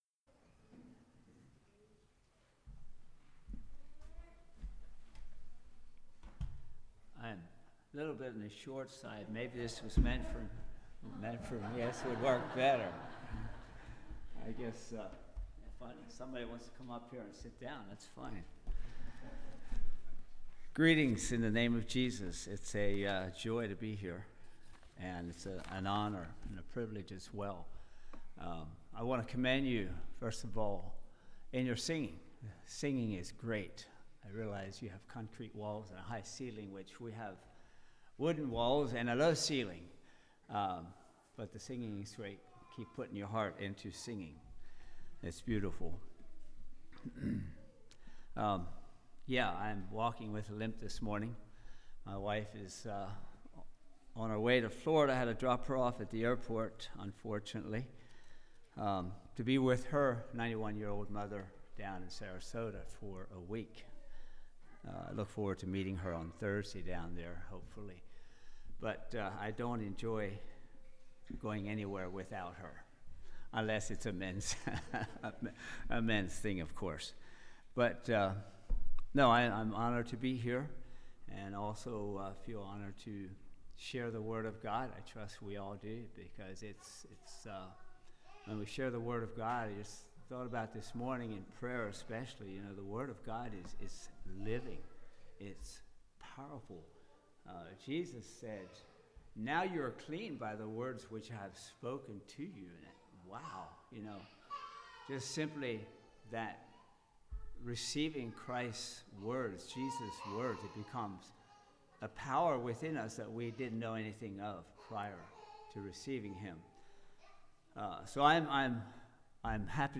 Passage: Matthew 3:13-17 Service Type: Message